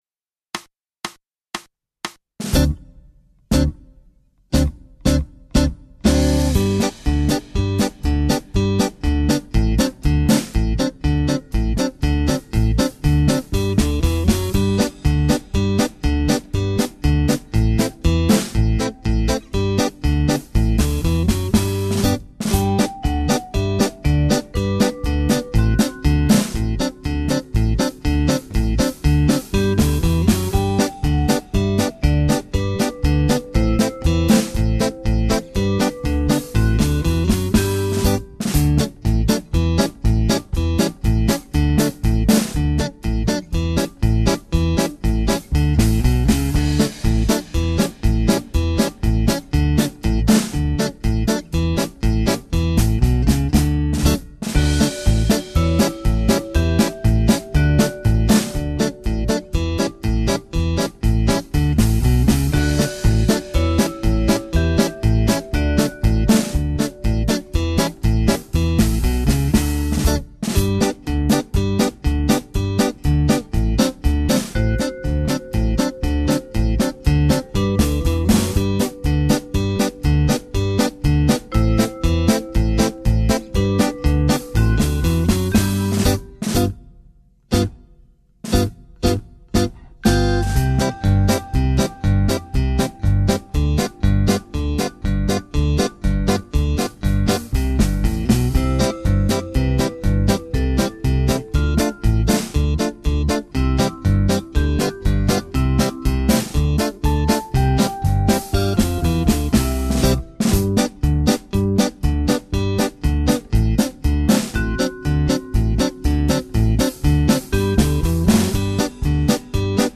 Genere: Polka
Scarica la Base Mp3 (2,70 MB)